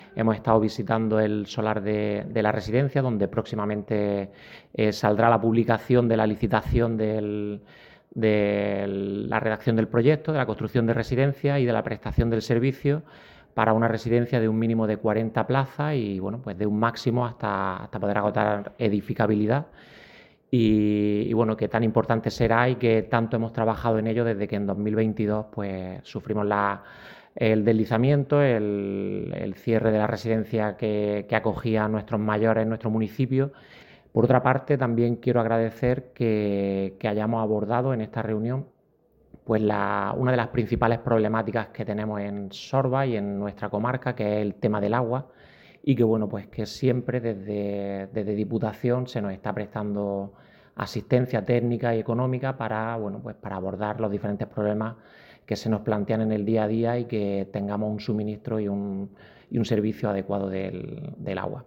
Alcalde-de-Sorbas-Diputacion.mp3